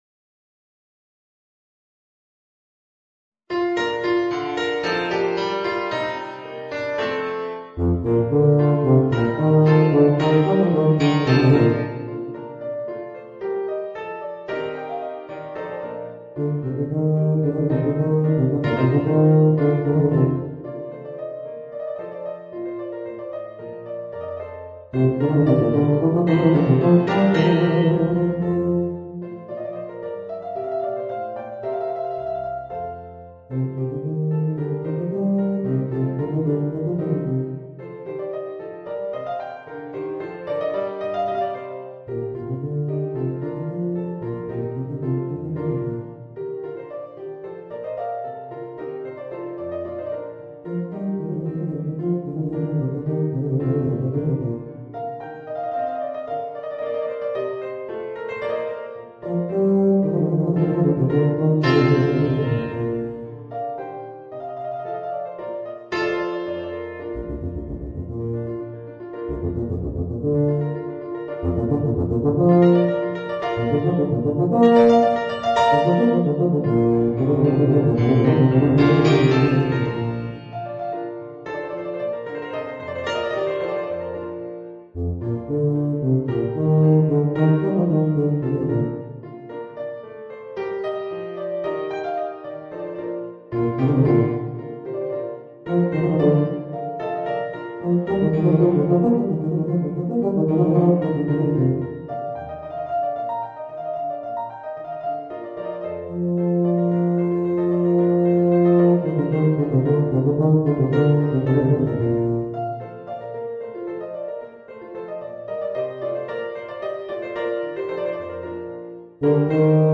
Voicing: Tuba and Organ